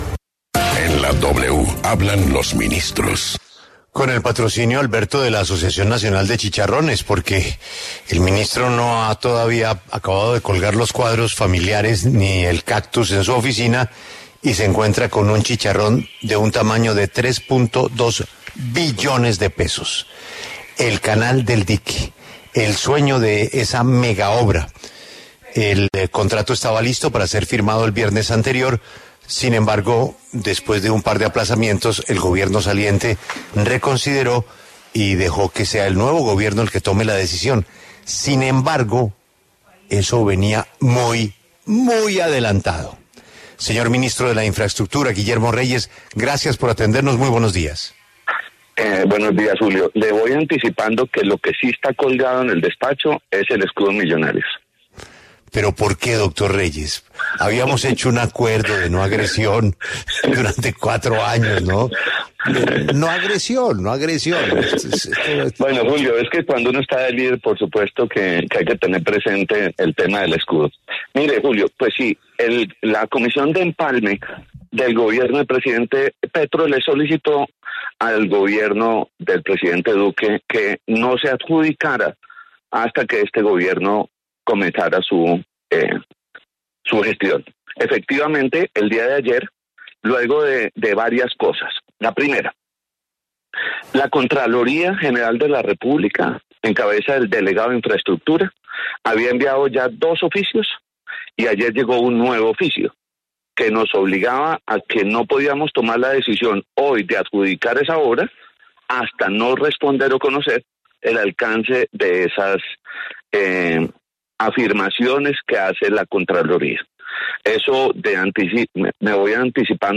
Guillermo Reyes, ministro de Transporte, se pronunció en La W sobre la adjudicación de la APP del Canal del Dique, que fue aplazada hasta el próximo 12 de septiembre.